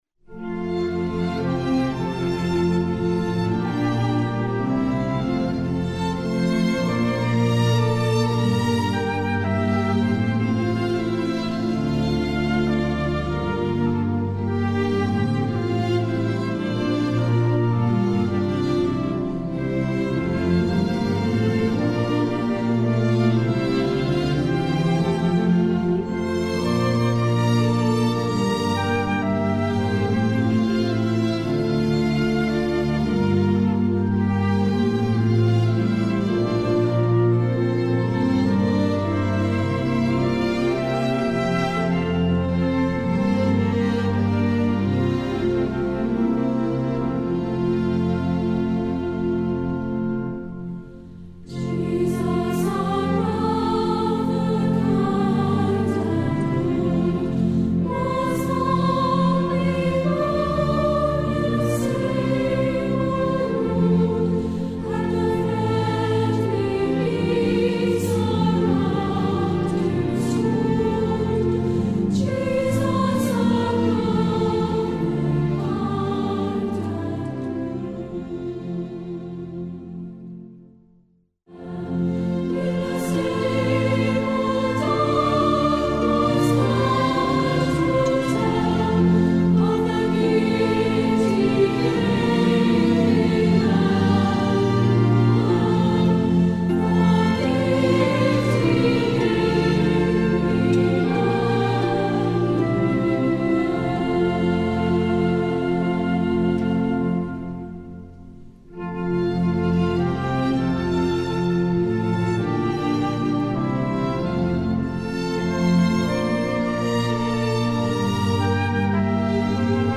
Voicing: Unison Voices, Four Soloists